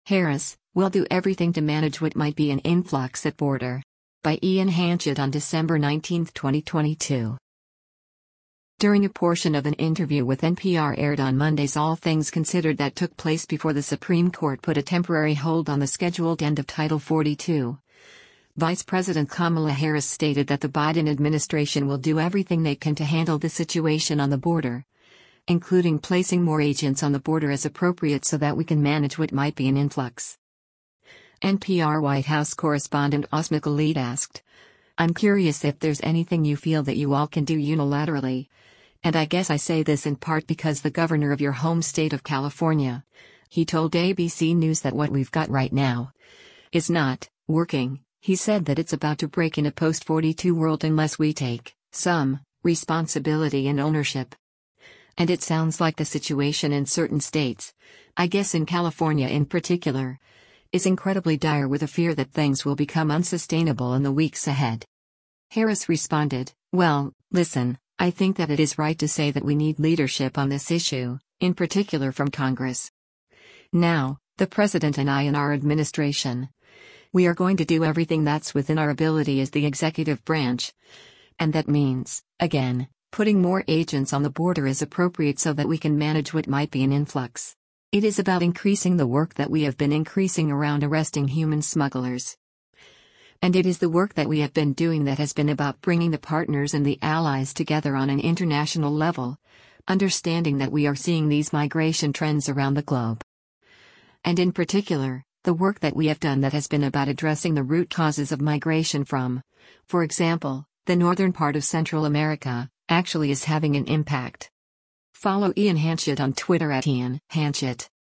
During a portion of an interview with NPR aired on Monday’s “All Things Considered” that took place before the Supreme Court put a temporary hold on the scheduled end of Title 42, Vice President Kamala Harris stated that the Biden administration will do everything they can to handle the situation on the border, including placing “more agents on the border as appropriate so that we can manage what might be an influx.”